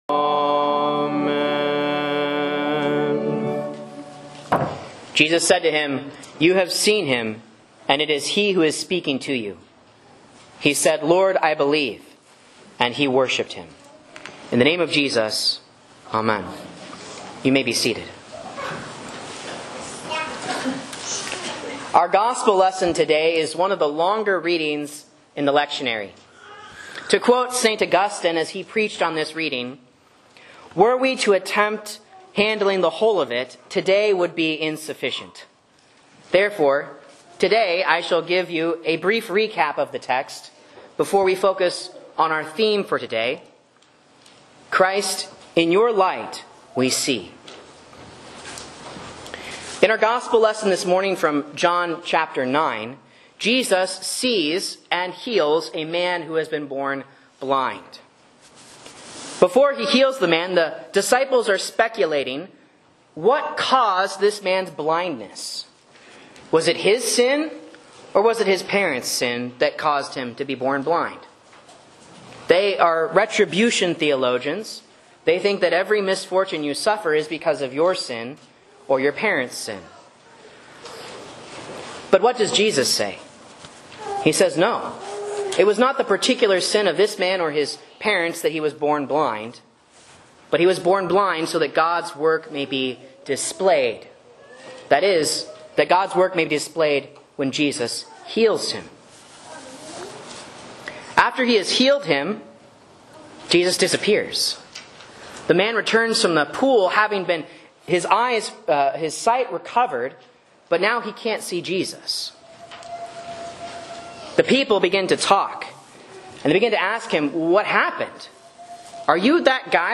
A Sermon on John 9:1-41 for Lent 4 (A)